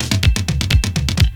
DRUMFILL10-R.wav